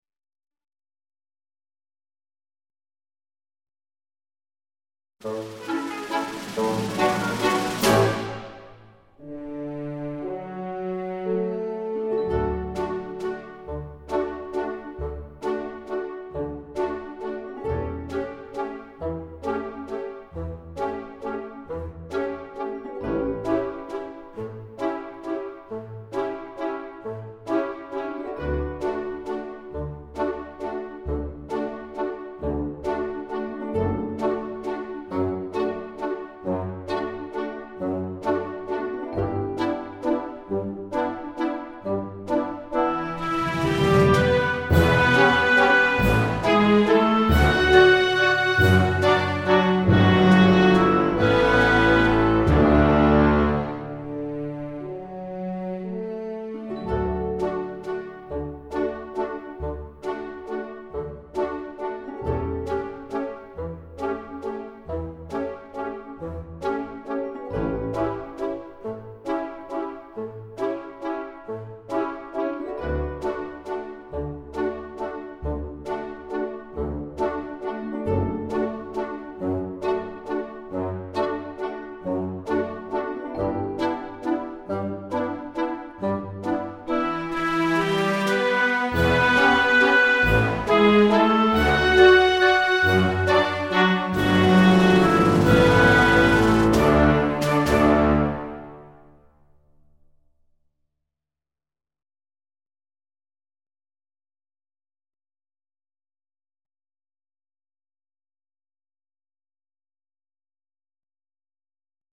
13 The Blue Danube (Backing Track)